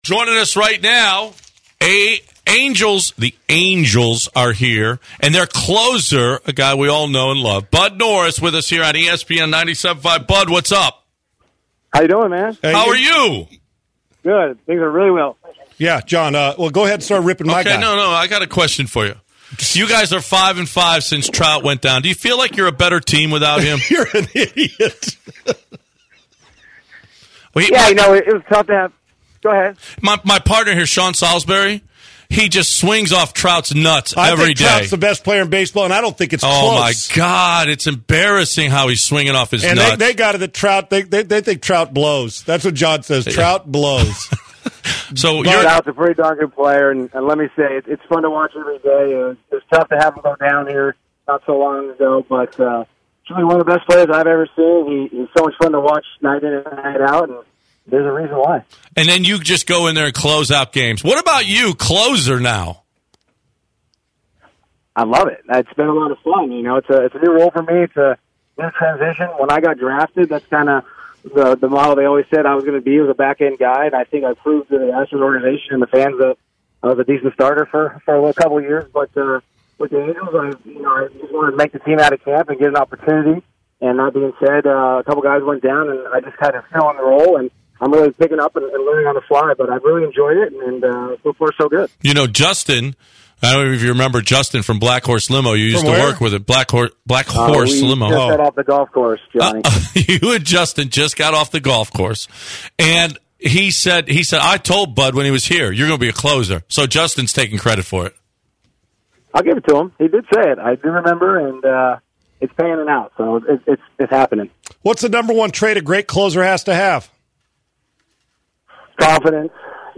Bud Norris Interview